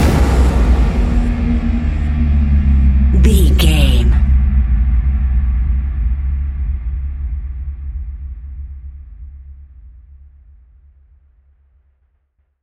Fast paced
In-crescendo
Ionian/Major
C♯
dark ambient
EBM
synths
Krautrock